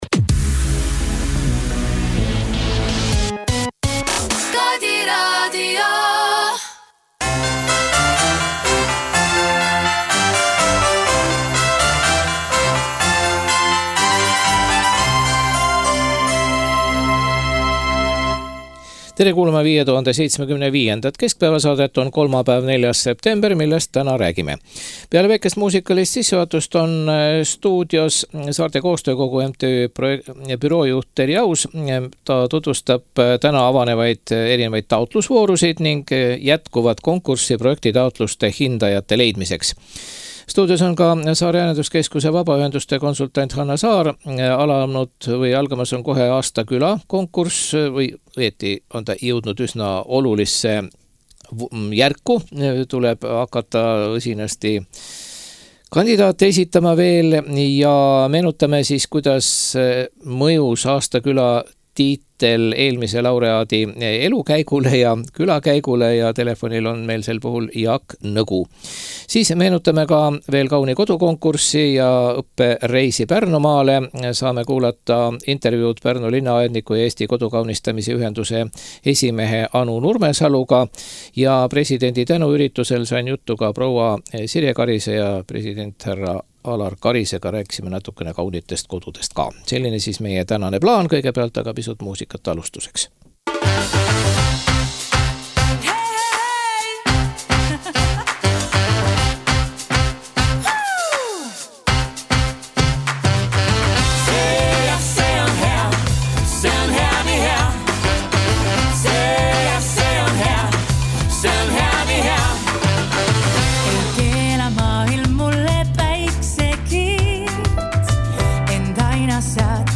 Presidendi tunnustusüritusel sain jutule ka pr. Sirje Karise president Alar Karisega.